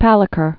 (pălĭ-kər)